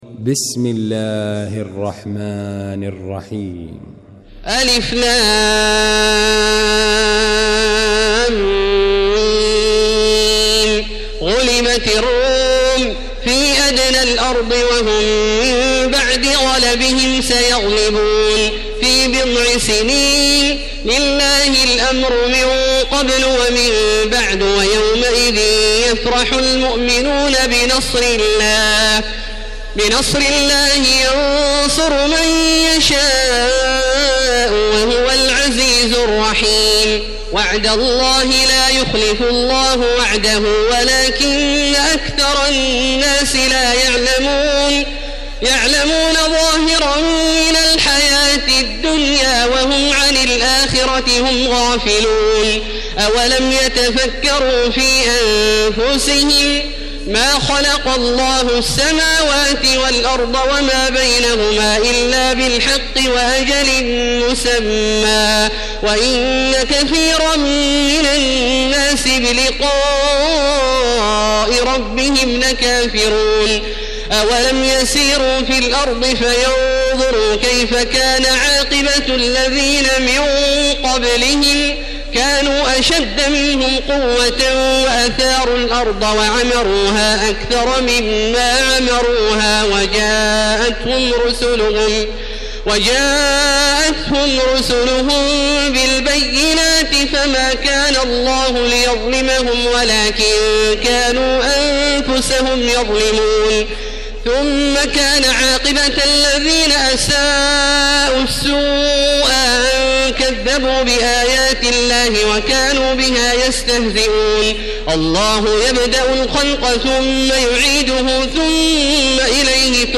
المكان: المسجد الحرام الشيخ: فضيلة الشيخ عبدالله الجهني فضيلة الشيخ عبدالله الجهني الروم